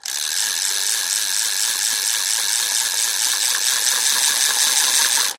Звук: сворачиваем удочку